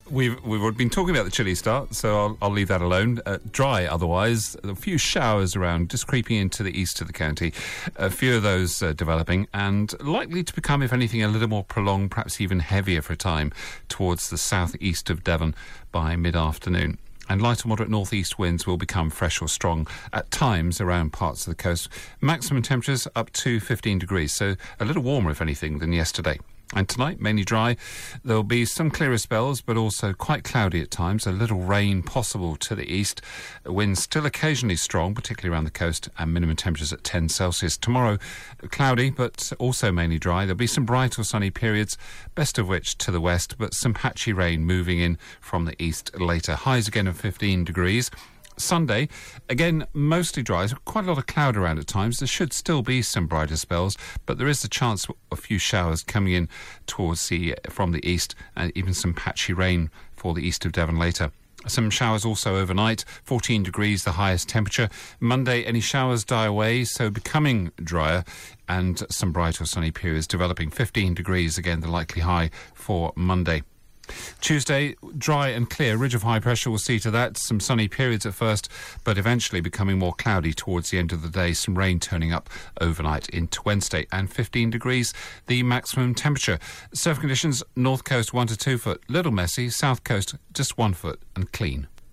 5 day forecast for Devon from 8.35AM on 11 October